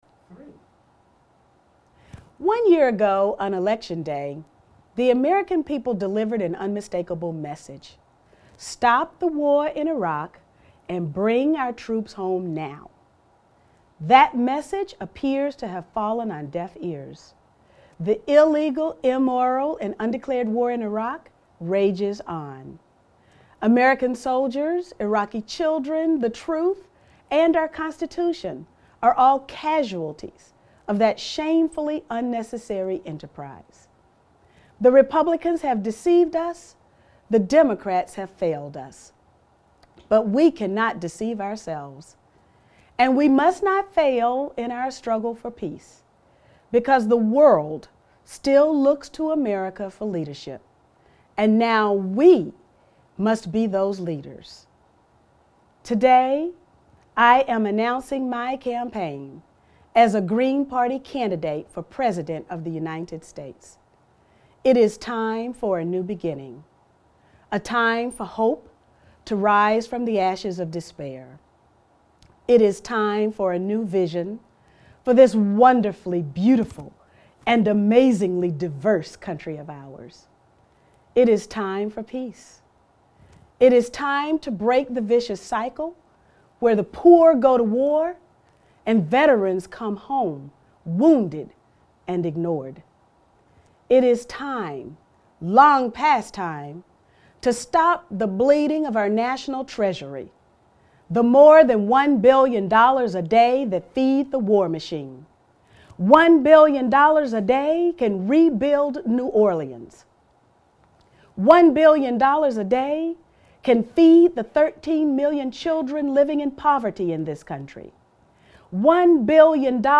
Your video speech is great and the Green's platform is really what most of want if the general public would take the time and check it out.